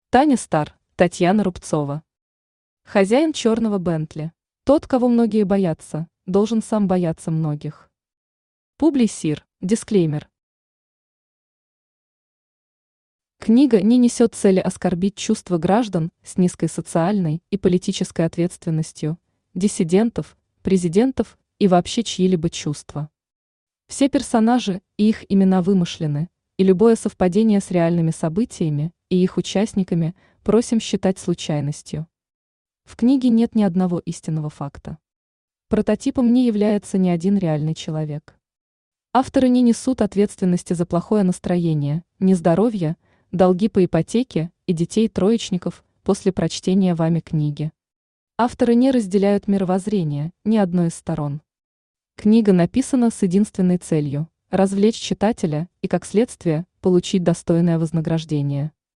Аудиокнига Хозяин чёрного Бентли | Библиотека аудиокниг
Aудиокнига Хозяин чёрного Бентли Автор Таня Стар Читает аудиокнигу Авточтец ЛитРес.